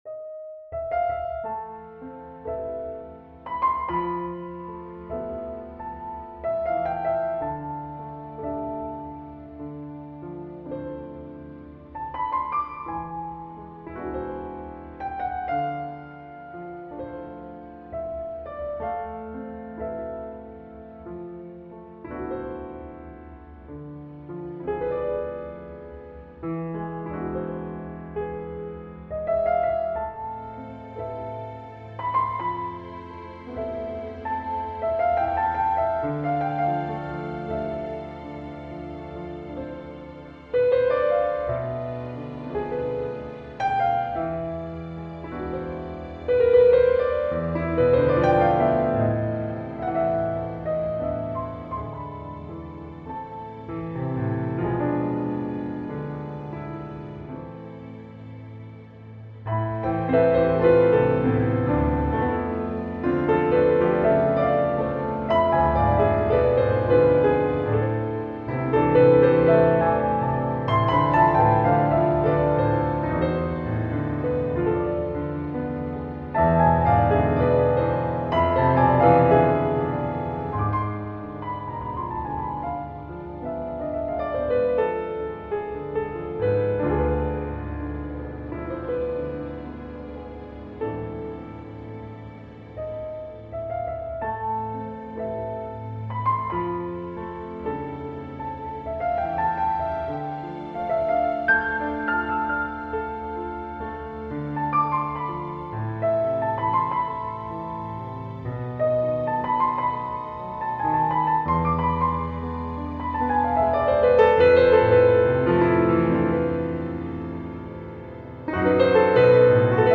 piano piece
a Chopinesque jazz improvisation